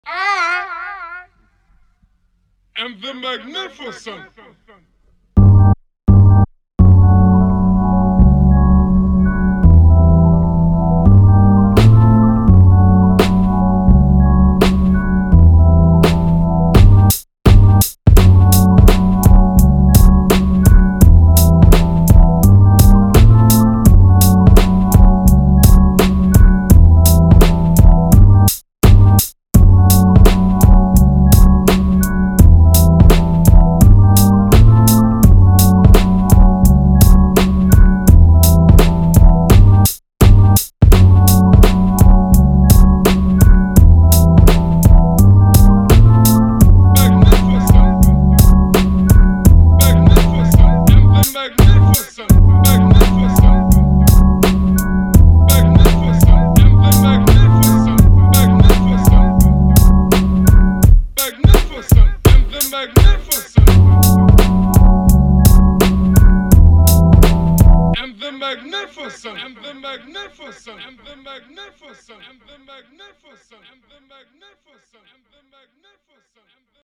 beat tape